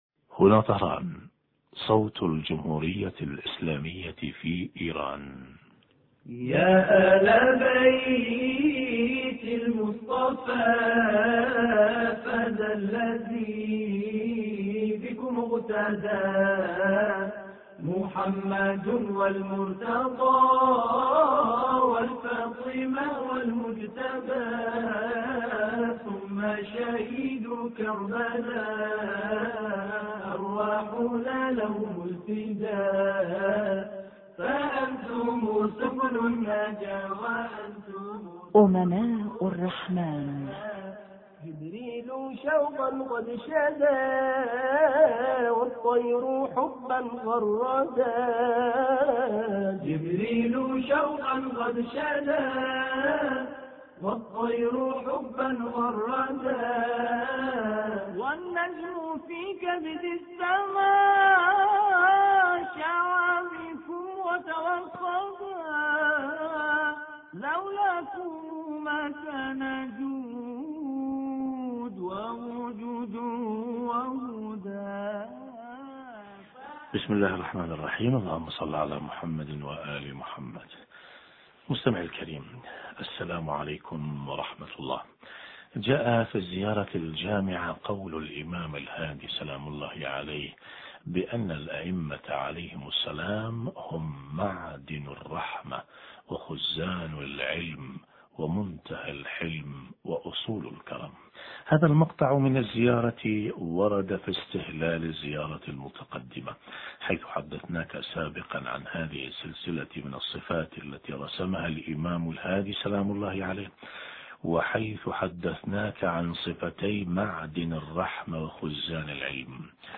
أما الآن نتابع تقديم برنامج امناء الرحمن بهذا الاتصال الهاتفي